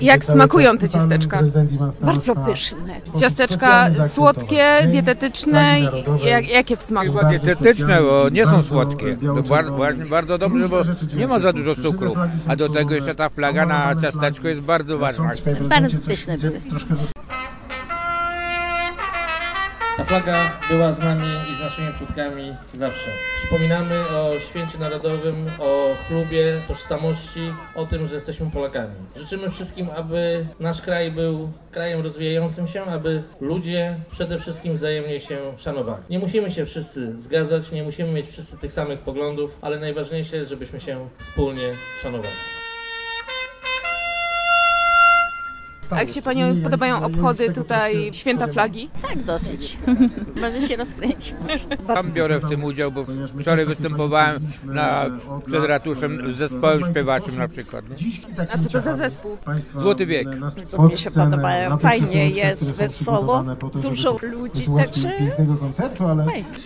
Jak głogowianie oceniają tegoroczne obchody Święta Flagi? - Tak dosyć, może się rozkręci - mówi jedna z mieszkanek. - Mi się podoba. Fajnie jest, wesoło, dużo ludzi - ocenia inna głogowianka.